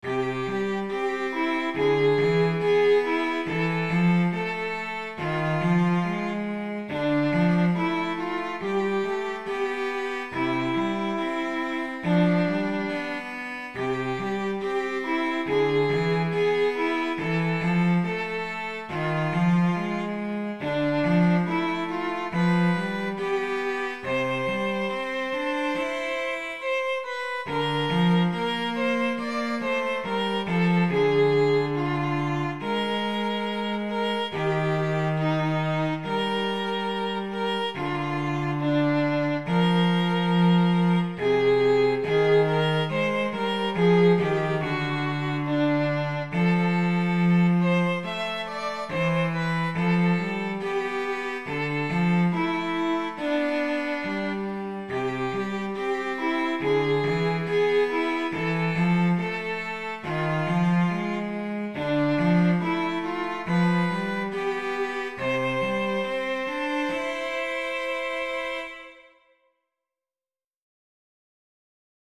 minuet for violin and cello.
minuet_2_violin_cello.mp3